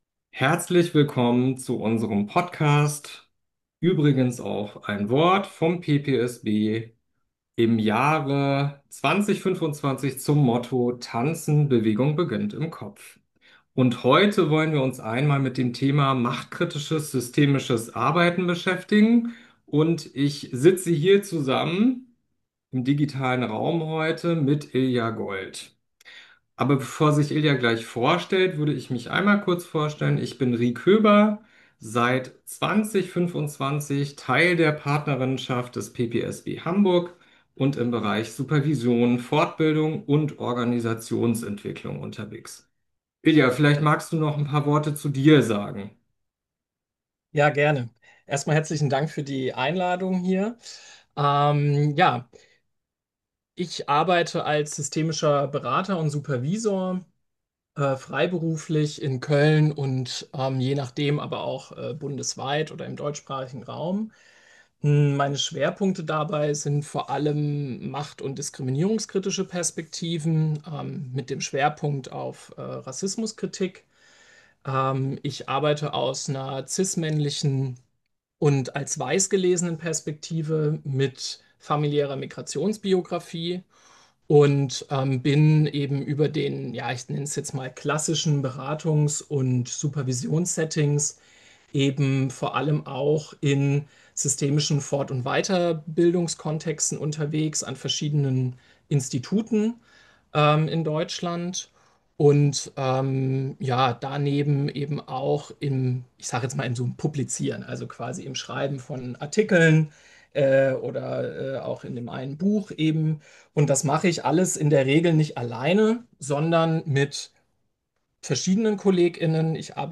Podcast - Übrigens... Teil 22 - Ein Interview